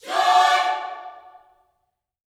JOY CHORD2.wav